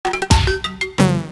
Entonces la imagen llevaba implícita otra melodía creada al efecto, confirmando que la cosa estaba chunga mientras un escalofrío recorría tu espalda.
La muy vacilona secuencia (casi de dibujos animados) en el caso de los Quadra AV:.
Macintosh-Quadra-AV-Death.m4a